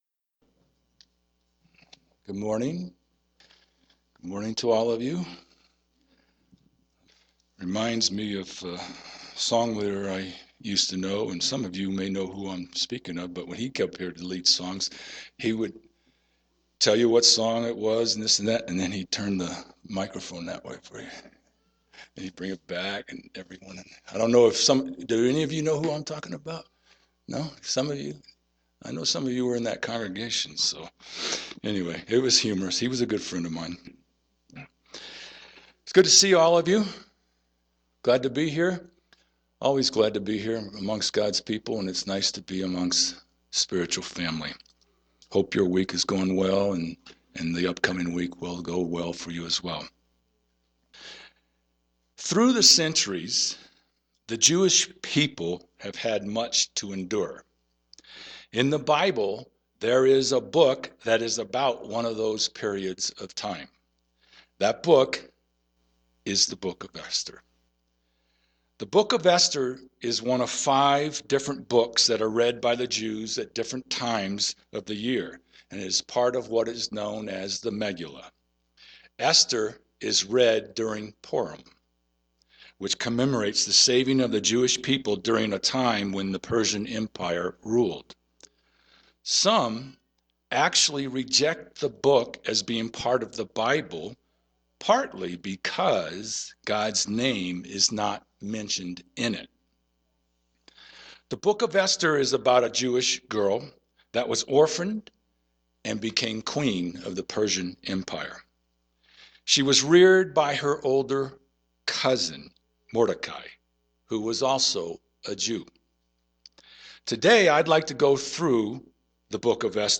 The book of Esther is about a Jewish girl that was orphaned and became Queen of the Persian Empire. This sermon examines some of the background, history and lessons for us today.